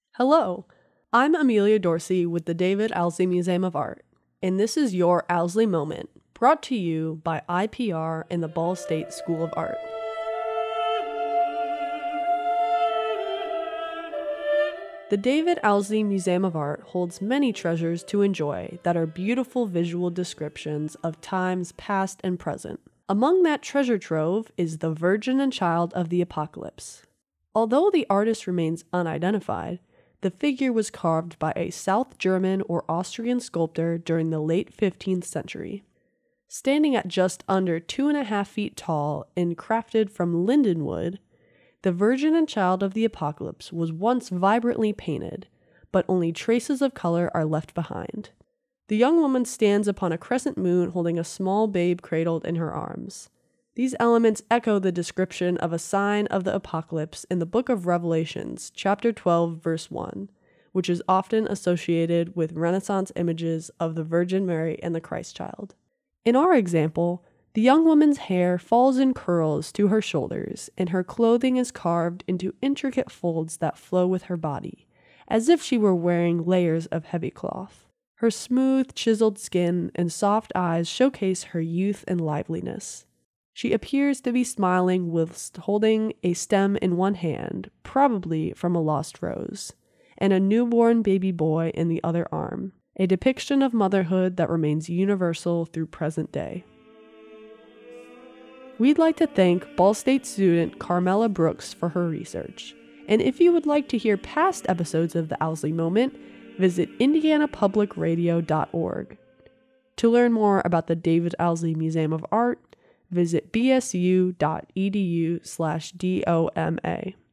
Music used in this episode:
Missa Gaudeamus